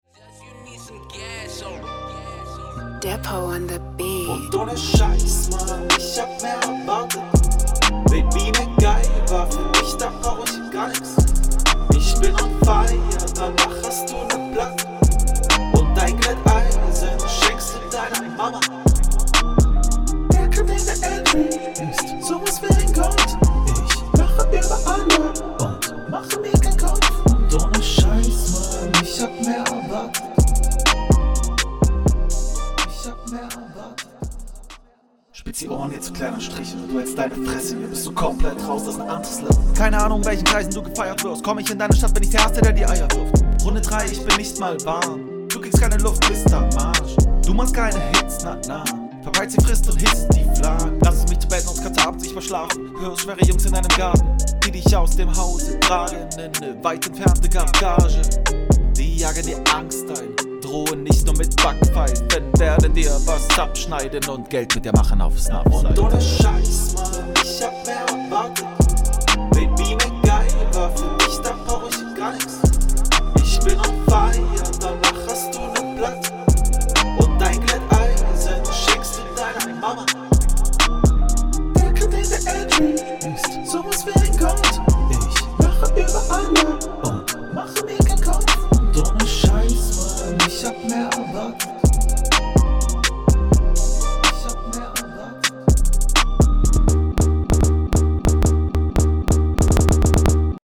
Flowlich geil, hook is chillig. die Rapparts finde ich an sich gut, wobei da ein …
Uff das Intro, singen ist nicht deins, grad die Kofstimme ist unangenehm.
Hohe Voice ganz lustig.